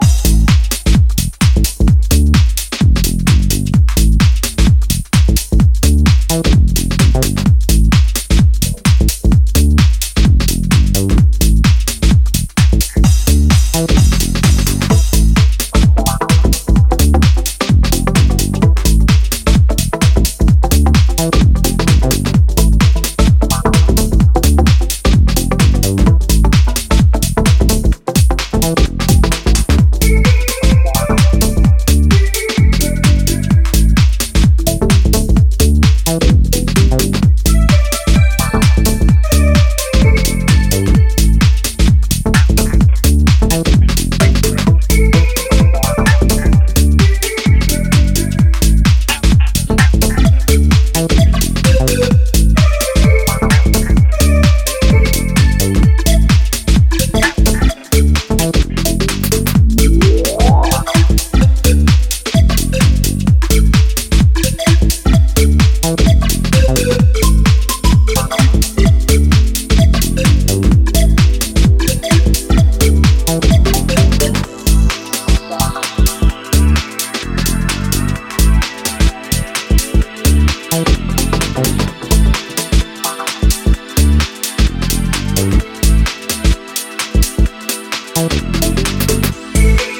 ジャンル(スタイル) HOUSE / TECH HOUSE